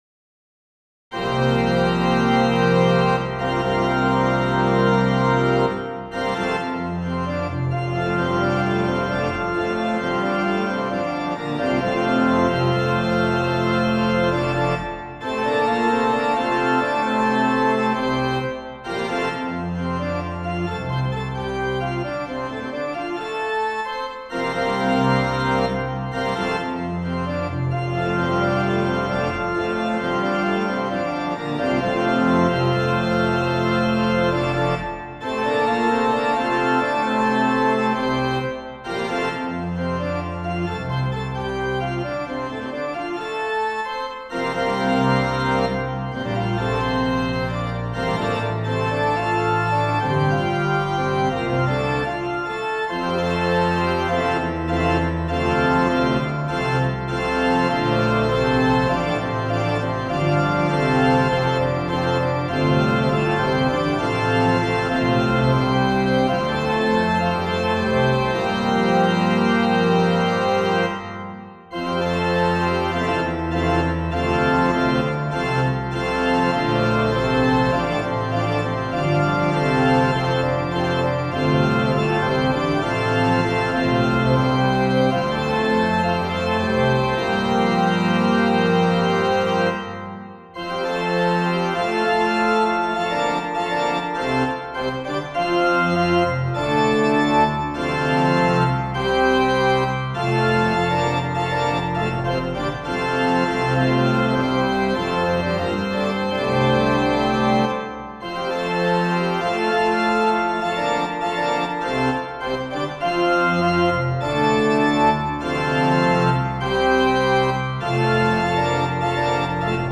for organ
Intended for a full registration, one reads:  "In Sacred Harp singing, loud is usually good, and louder is better.